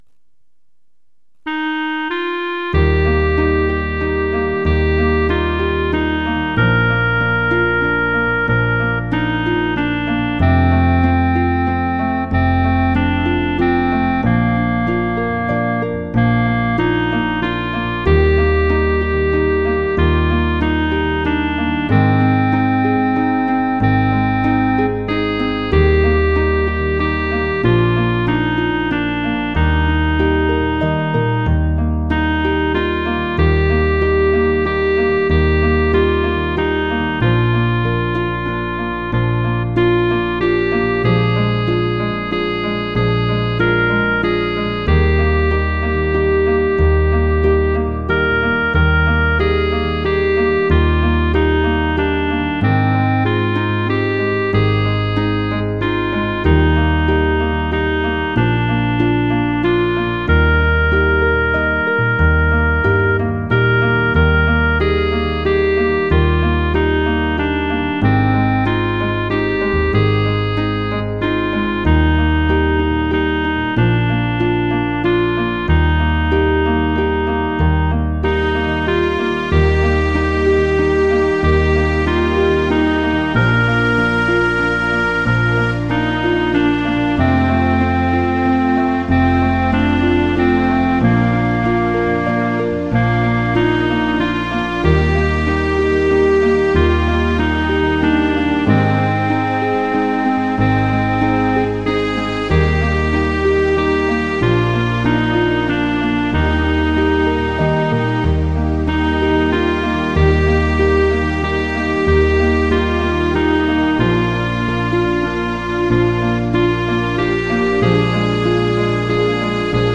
MP3 の速度は歌集の指示よりも遅く作ってあります。
◆　８分の６拍子　：　５拍目から始まります。
●　「イエズス」は「イエ・ズ・ス」と三音で発音します。